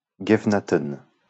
Guevenatten (French pronunciation: [ɡevənatən]